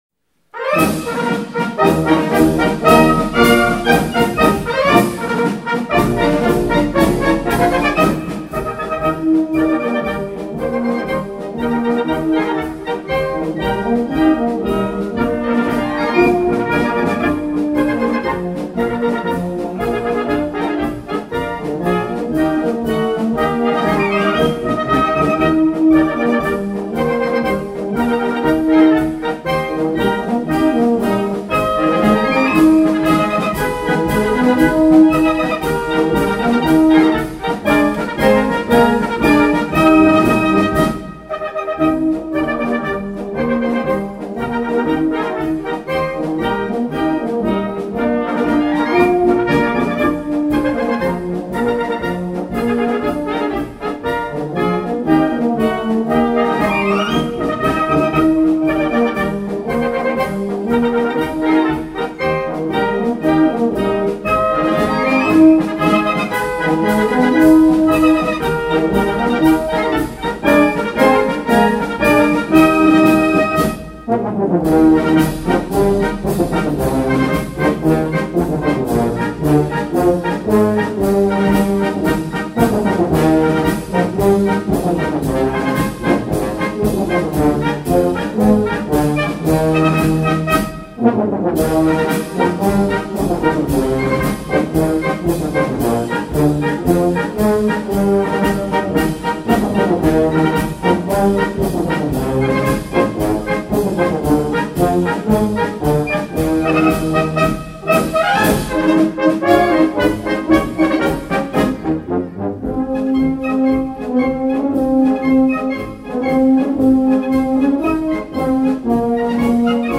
Jahreskonzert 2019
von E. Lindbichler